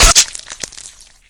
arrow_hit.ogg